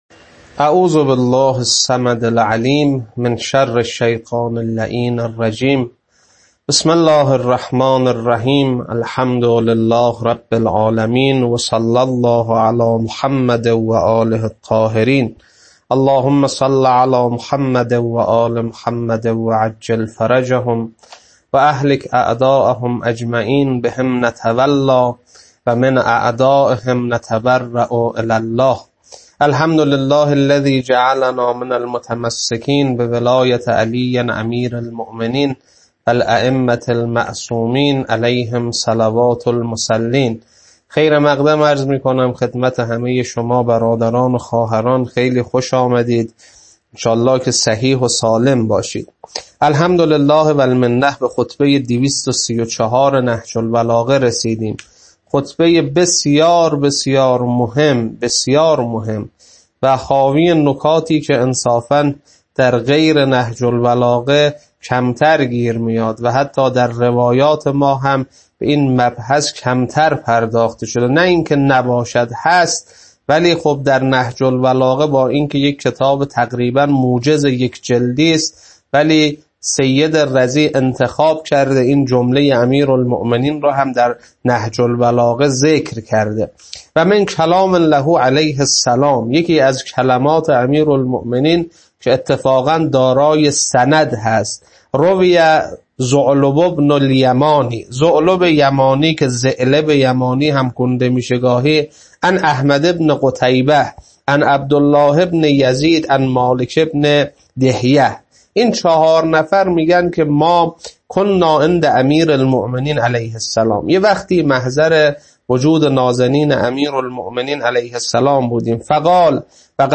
خطبه 234.mp3